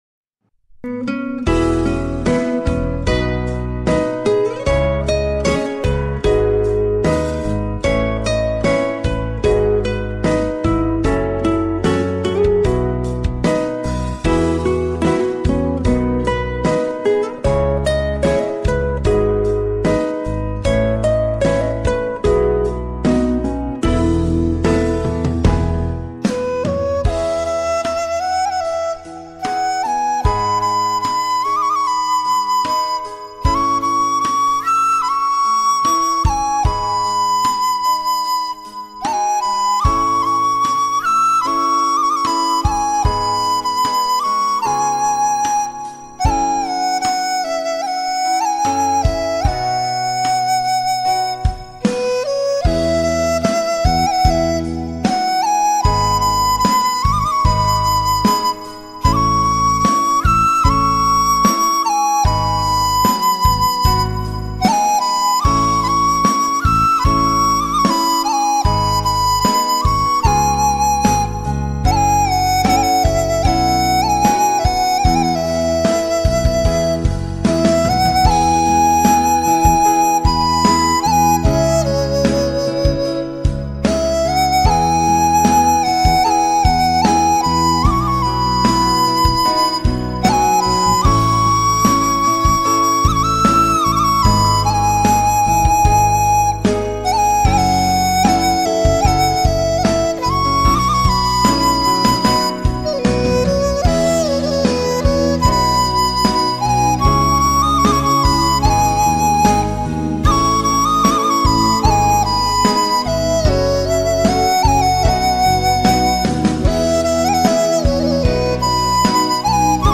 Sáo Trúc Dizi hào hùng, phong trần.
bản nhạc không lời chất lượng cao